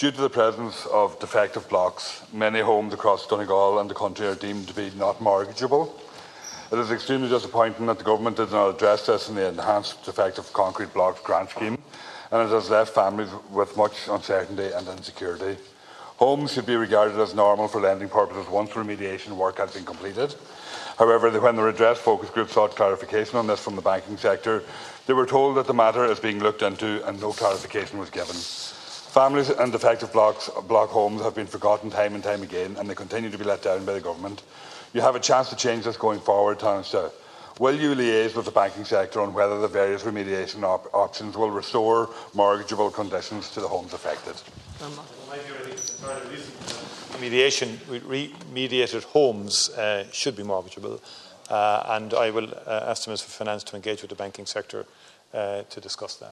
In the Dail last evening, Donegal Deputy Thomas Pringle urged Tanaiste Michael Martin to address the concerns……..